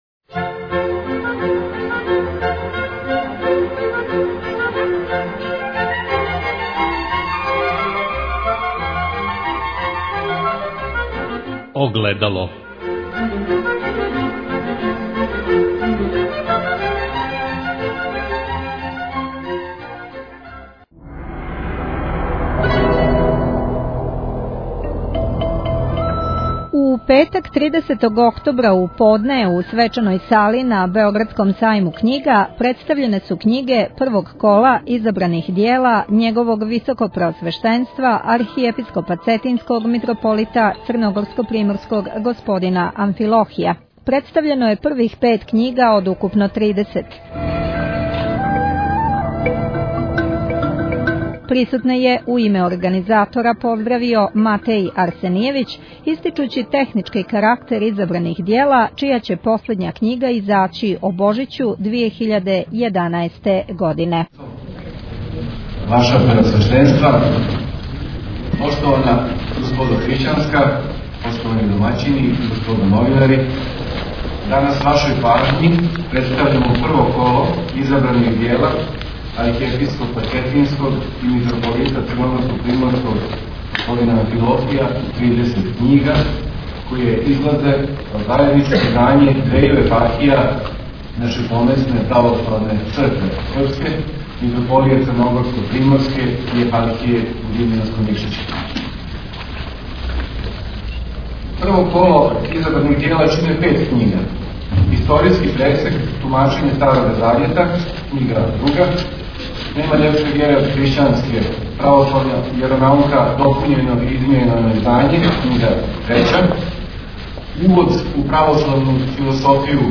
Запис са промоције првих пет књига првог кола Изабраних дјела Његовог Високопреосвештенства Архиепископа Цетињског Митрополита Црногорско-приморског Г. Амфилохија која је одржана у петак 30. октобра у свечаној сали на Београдском сајму књига.